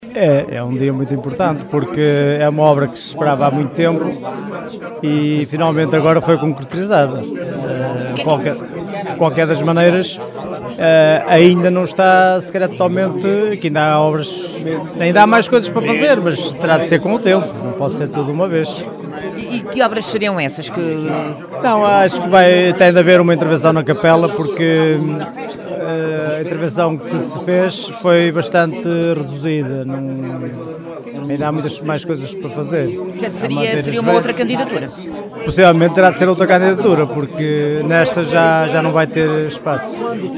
Já o presidente da União de Freguesias das três Argas diz que agora estão criadas as condições para que seja desenvolvida uma estratégia de valorização da Serra D’Arga. Contudo, Ventura Cunha fala na necessidade de uma intervenção na capela